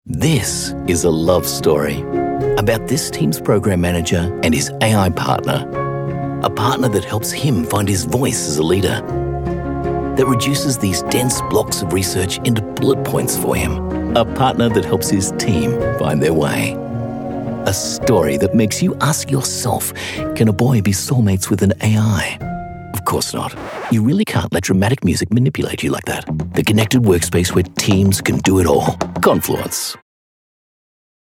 Announcer, conversational, non-announcer, guy next door, energy, high energy, Believable, Familiar, Natural, Upbeat, Real Person, Comforting, Friendly, Smooth, Sports, Persuasive, Classy, Authoritative, Big, Ba...